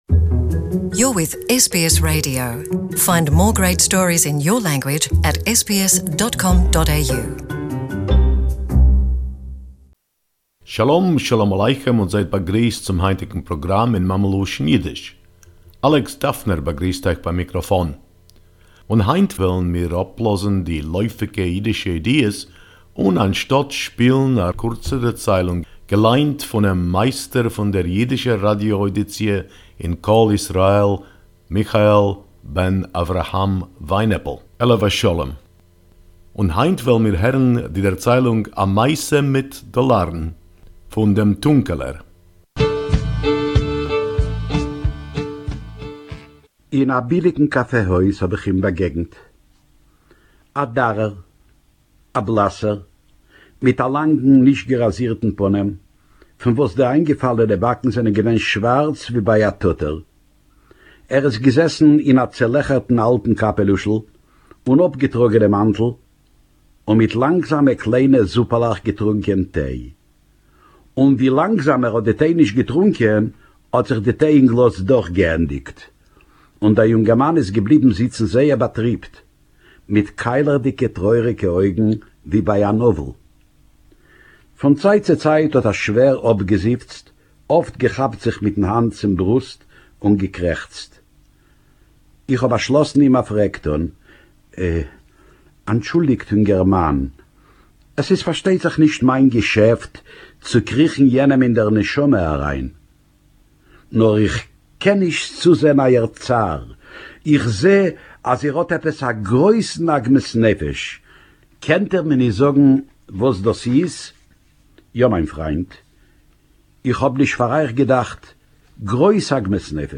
Yiddish story “A Story about Dollars” by Der Tunkeler